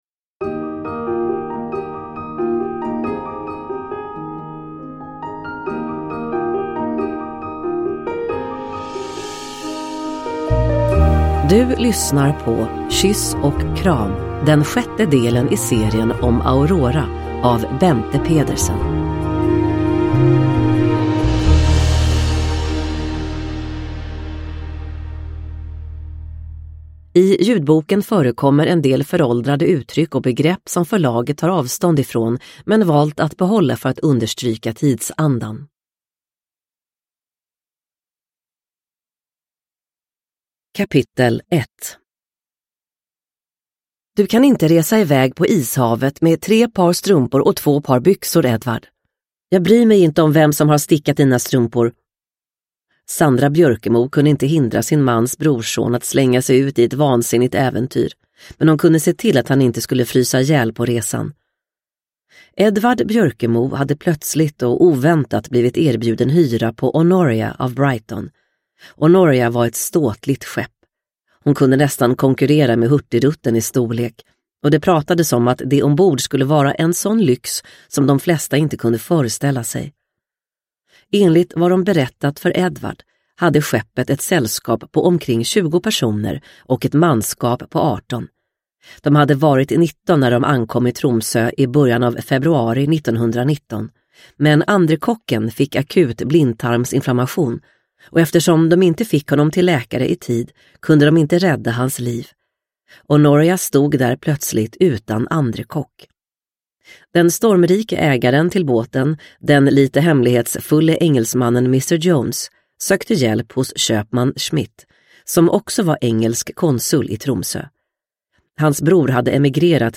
Kyss och kram – Ljudbok